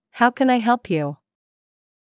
ﾊｳ ｷｬﾝ ﾅｲ ﾍﾙﾌﾟ ﾕｳ